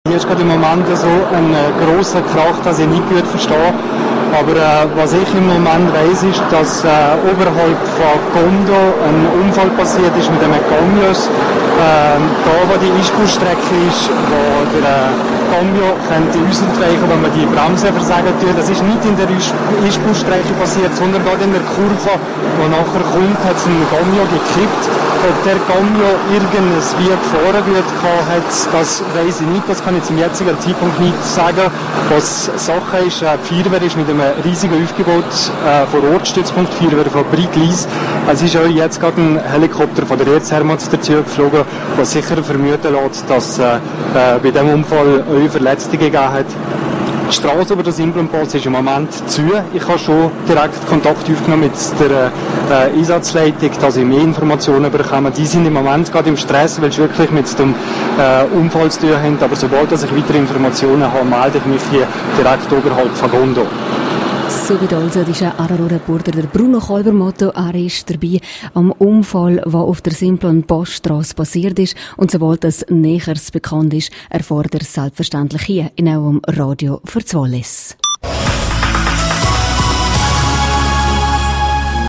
13650_News.mp3